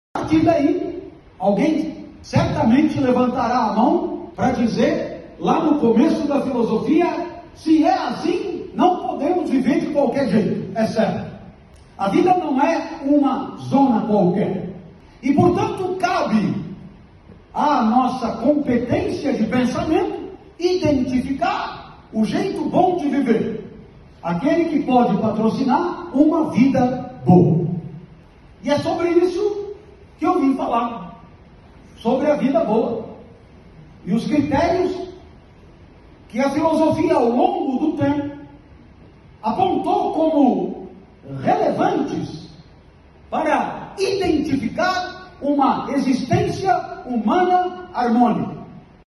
Para um auditório lotado, Barros Filho trouxe o tema “A Vida que Vale a Pena Ser Vivida”.